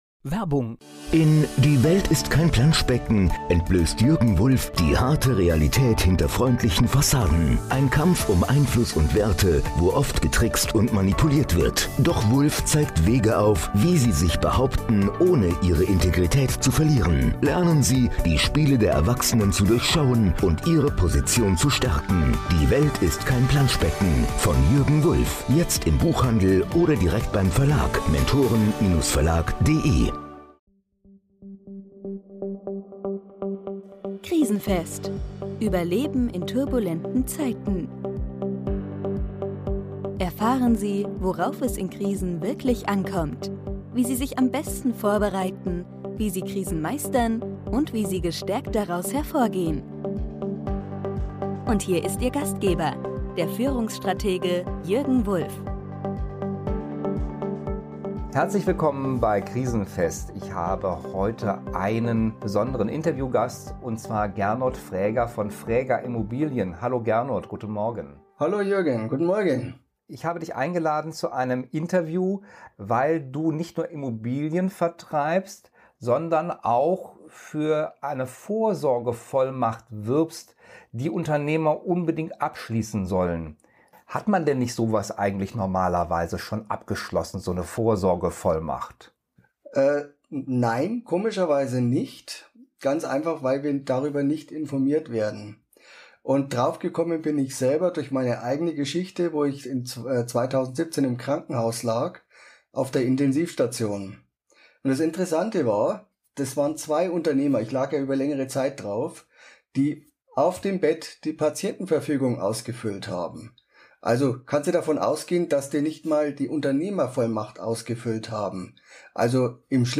Wie das ohne große Anwaltskosten möglich ist, erfahren Sie in diesem Interview in der Serie "Krisenfest".